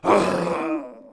fall_1.wav